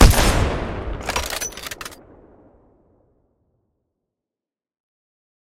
kraber_fire_1p.ogg